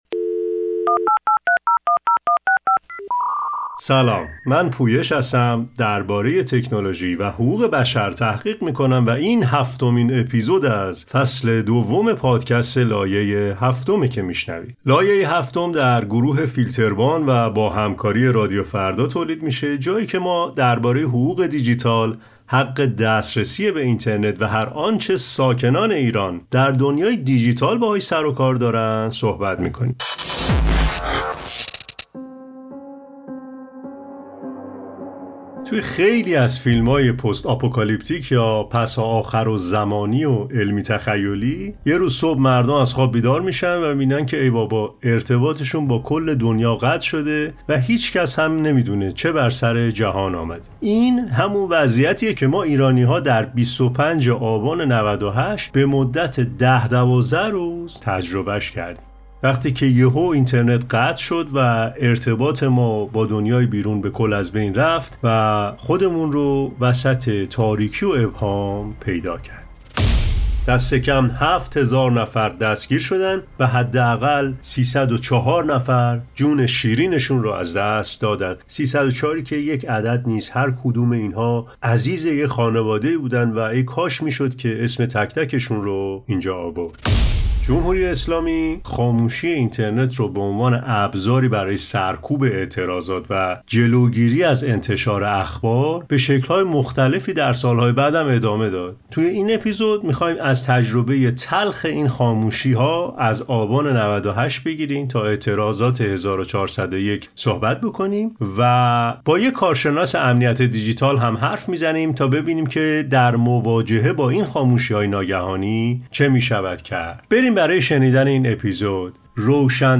به بهانه سالگرد قطعی اینترنت آبان ۹۸، در این اپیزود، روایت‌هایی از تجربه تلخ قطع اینترنت در ایران، از آبان ۹۸ تا مهاباد ۱۴۰۱ را می‌شنویم. همچنین، با یک متخصص امنیت دیجیتال هم‌صحبت شدیم تا راه‌های مقابله با سانسور و قطعی اینترنت را با هم مرور کنیم.